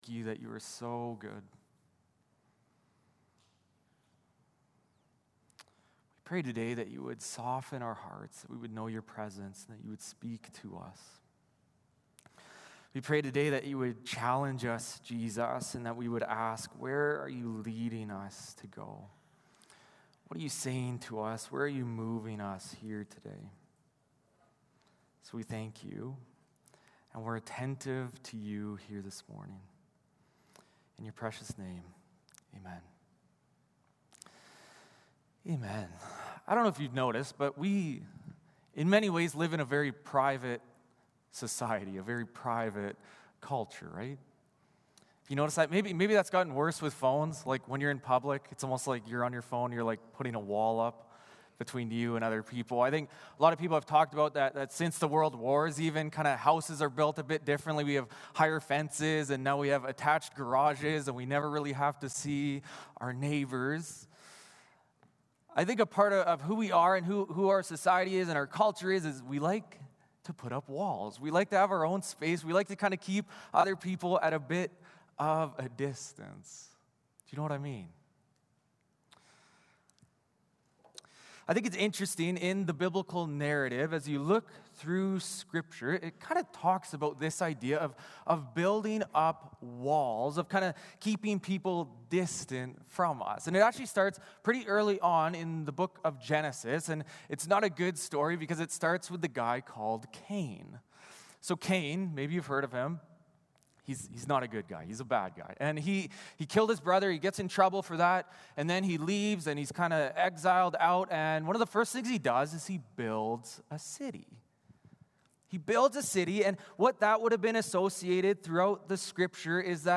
John 20:21 Service Type: Sunday Morning Service Passage